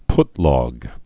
(ptlôg, -lŏg, pŭt-)